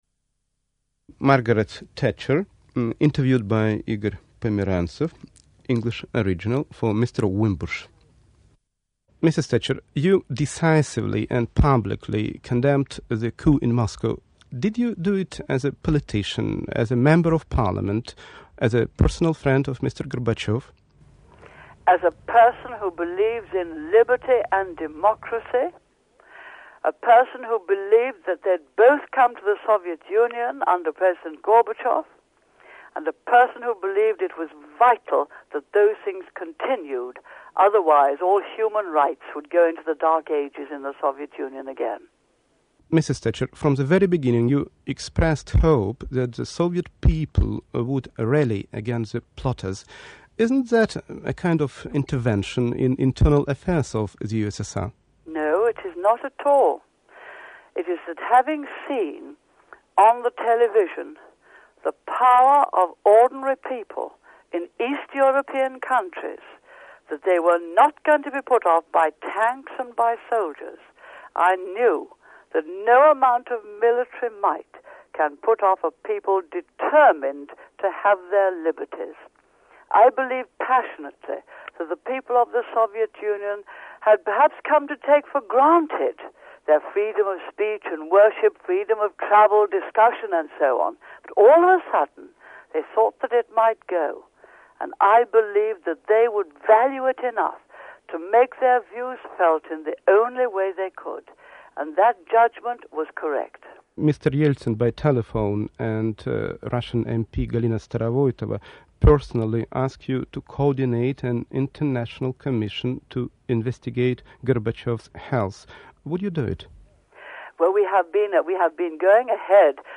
From The Archives: Thatcher Interviewed After 1991 Soviet Coup